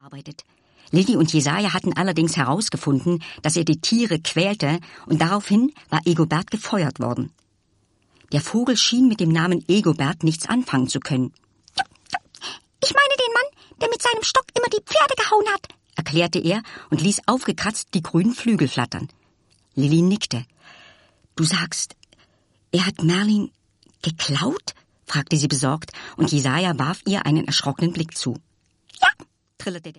Ravensburger Liliane Susewind - Mit Freunden ist man nie allein ✔ tiptoi® Hörbuch ab 6 Jahren ✔ Jetzt online herunterladen!